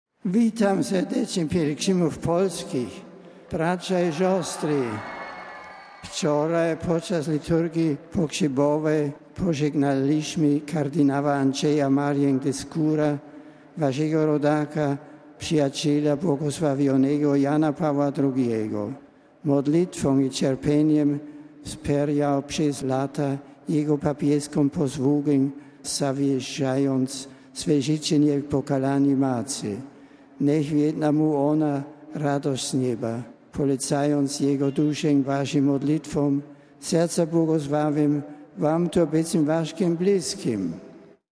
Ze względu na dużą liczbę pielgrzymów dzisiejsza audiencja ogólna odbyła się na Placu św. Piotra.
Zwracając się do Polaków, Benedykt XVI nawiązał do postaci zmarłego w ubiegłą sobotę kard. Andrzeja Marii Deskura.